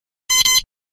Звуки градусника
Звук измерения температуры инфракрасным термометром с отображением результата на экране